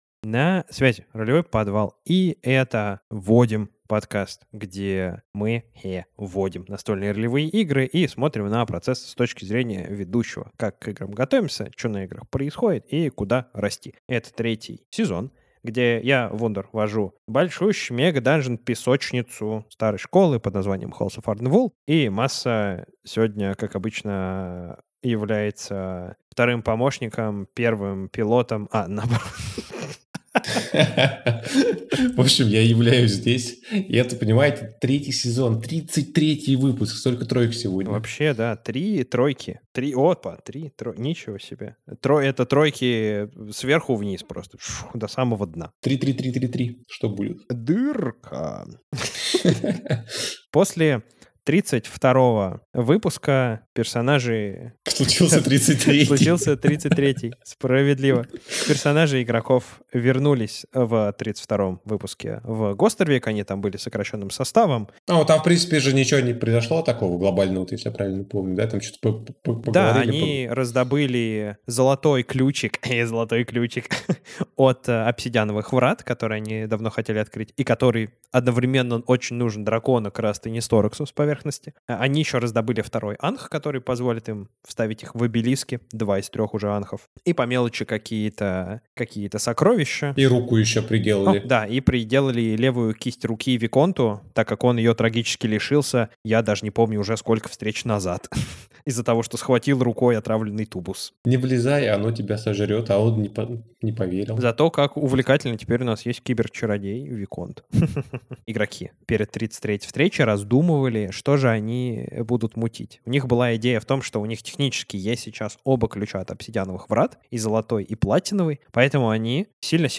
Издаём звуки бабуинов Обсуждаем скорость событий и ход времени в кампаниях-песочницах